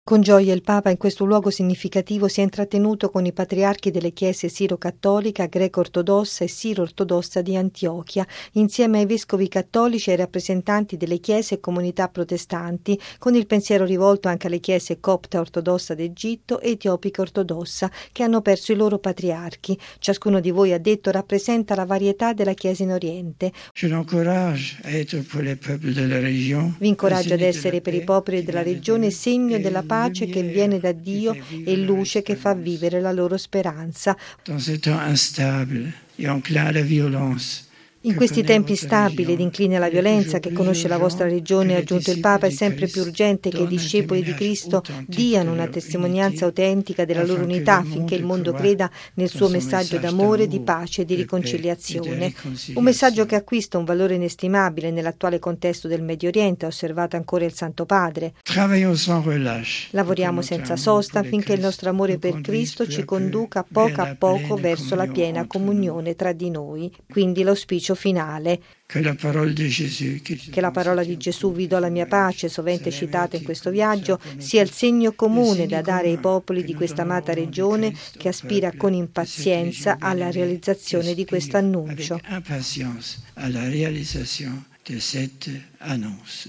◊   Prima di lasciare l’amato Paese dei Cedri, Benedetto XVI ha invocato l’unità per tutti i cristiani, perché il mondo creda nel messaggio di pace loro affidato. L’appello è risuonato tra le mura del monastero di Notre Dame de la Délivrance di Charfet, durante l’incontro ecumenico ospitato dal Patriarcato siro-cattolico, a Beirut. Il servizio